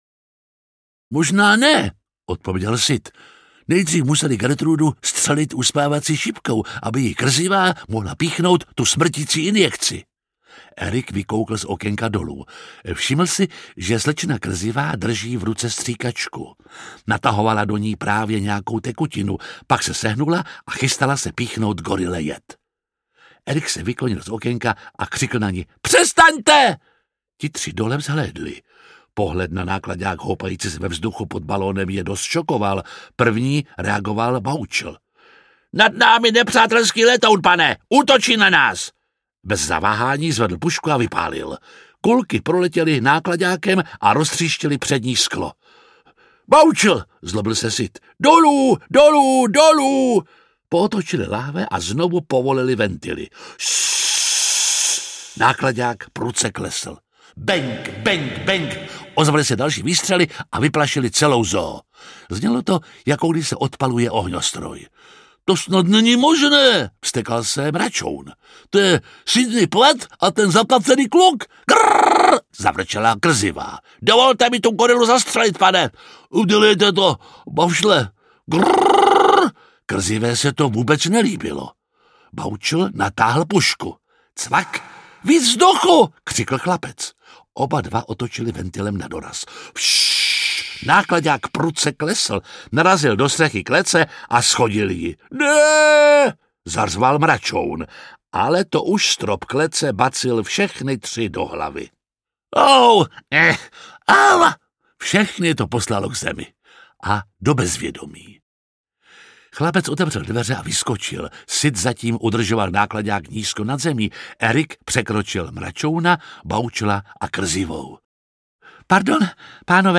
Operace Banány audiokniha
Ukázka z knihy
Čte Jiří Lábus.
Vyrobilo studio Soundguru.